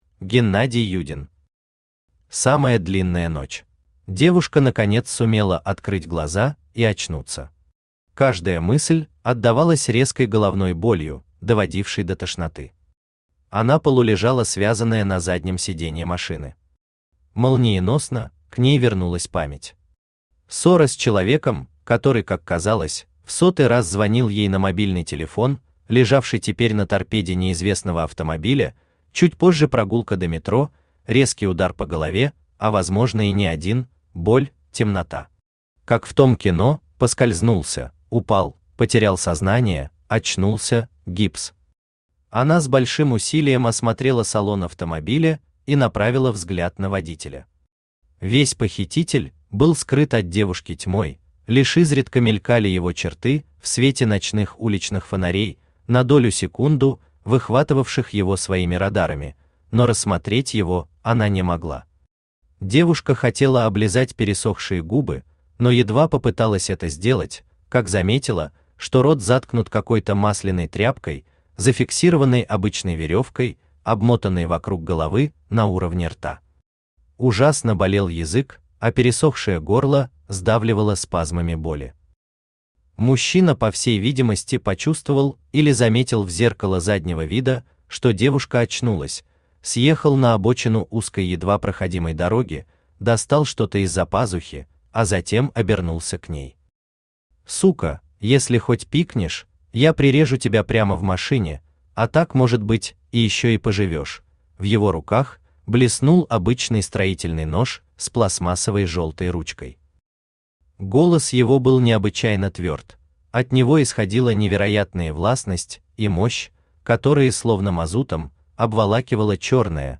Аудиокнига Самая длинная ночь | Библиотека аудиокниг
Aудиокнига Самая длинная ночь Автор Геннадий Юдин Читает аудиокнигу Авточтец ЛитРес.